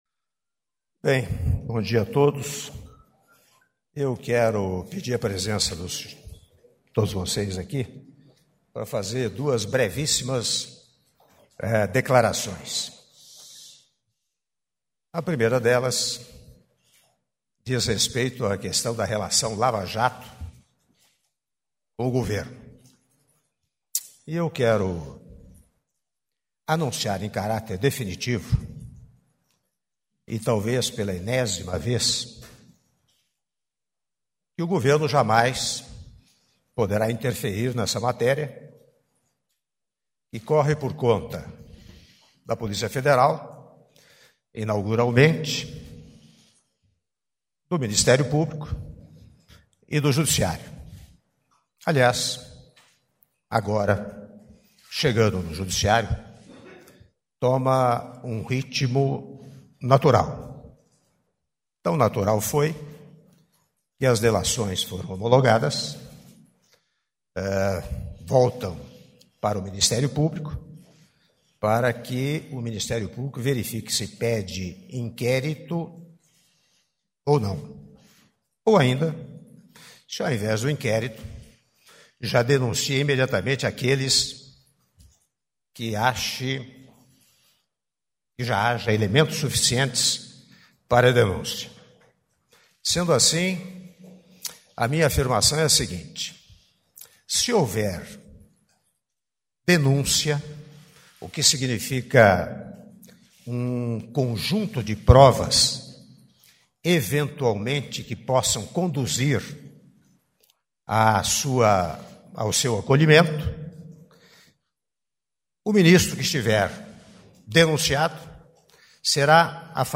Áudio da declaração à imprensa do presidente da República, Michel Temer, sobre regulamentação do direito à greve e afastamento de ministros - Brasília/DF (06min47s) — Biblioteca